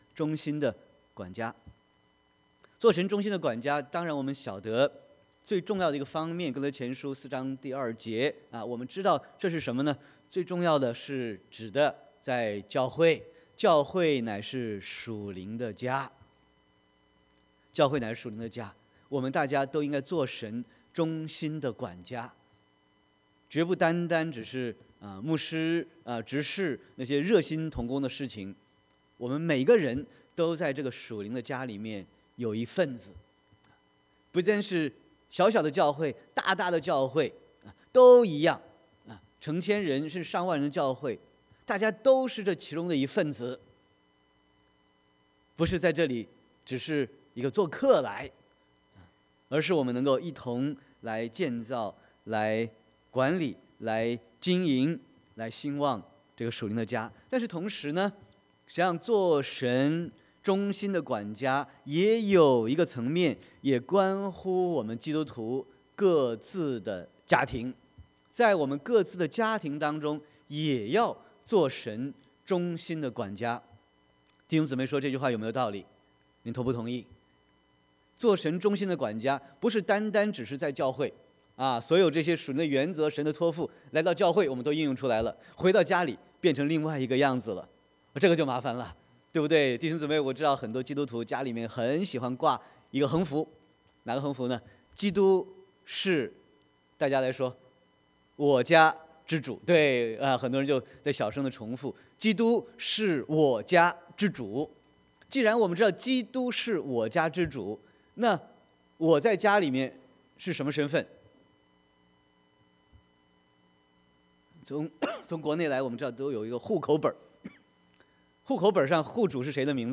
Sermon 02/17/2019